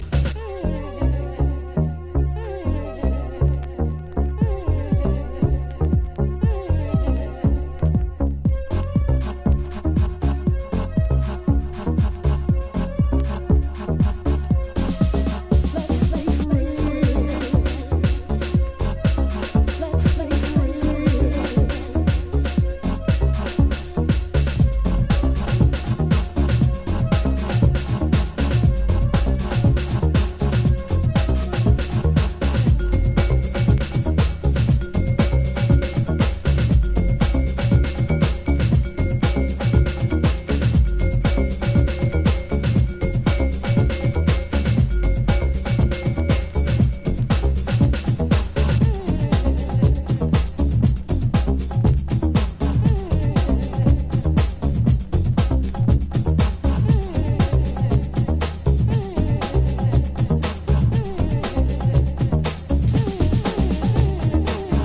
心に滲みるディープハウスオブソウル。